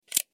دانلود آهنگ دوربین 2 از افکت صوتی اشیاء
دانلود صدای دوربین 2 از ساعد نیوز با لینک مستقیم و کیفیت بالا
جلوه های صوتی